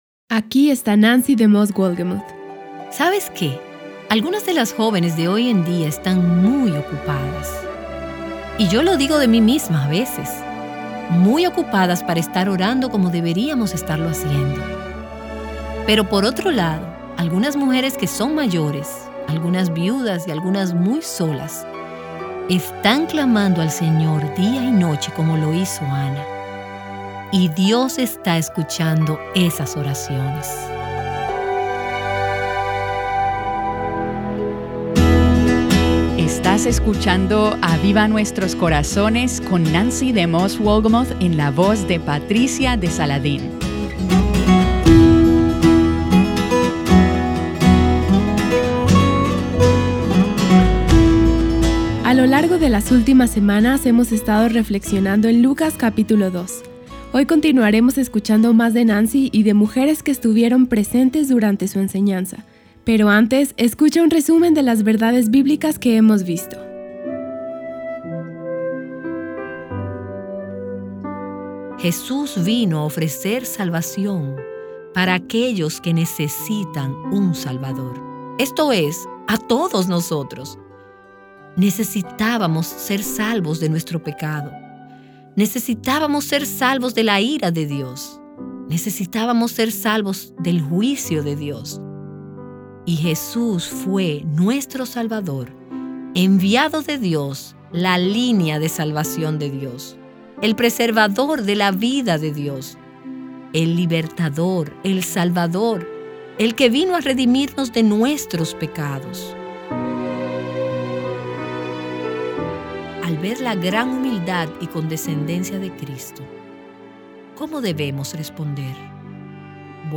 En el día de hoy escucharás a varias mujeres compartir el impacto que mujeres piadosas han tenido en sus vidas. Sé animada a través de estos testimonios en el episodio de hoy.